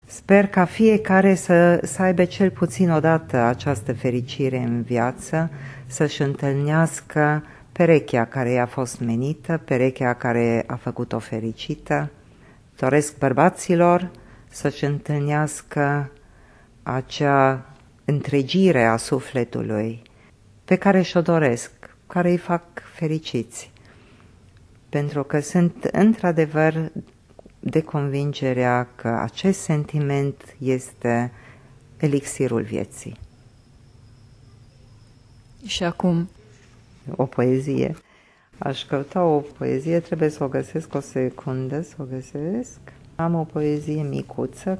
Reproducem doua fragmente din acest interviu: